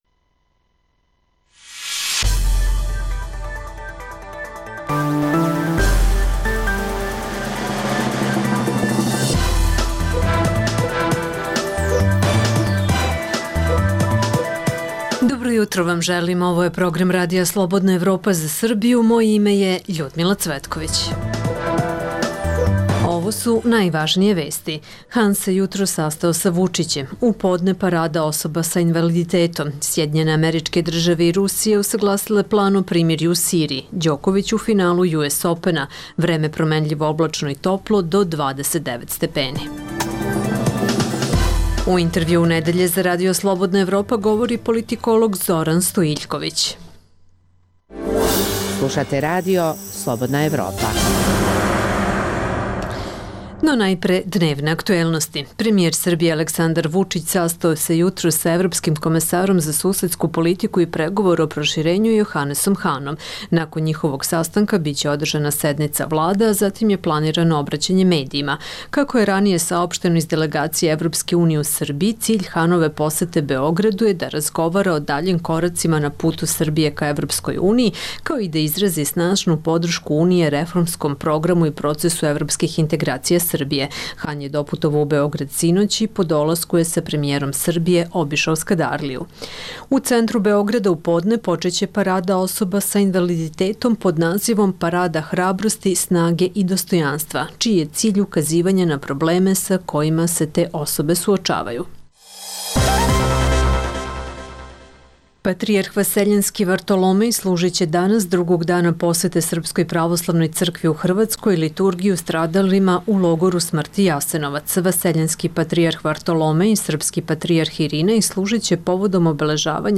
Uz dnevne aktuelnosti slušaćete Intervju nedelje RSE.